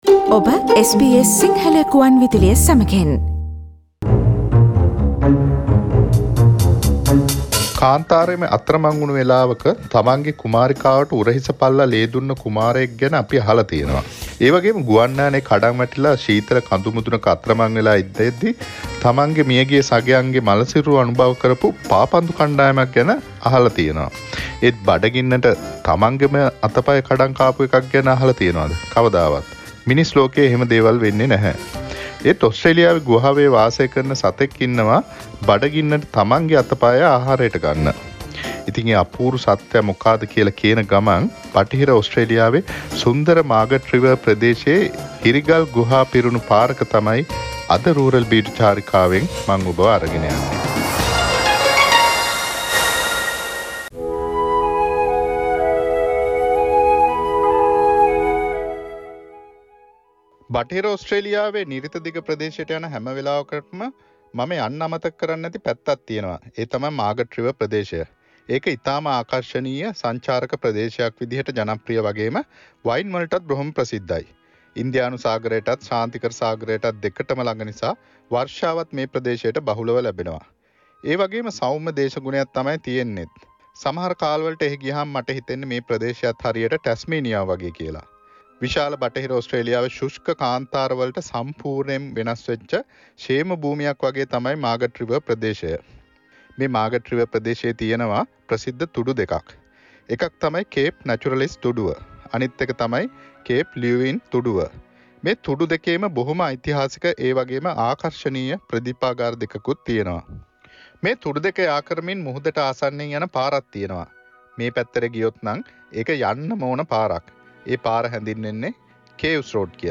SBS Sinhala Radio monthly radio tour taking you to a regional area in Australia in the last Friday of every month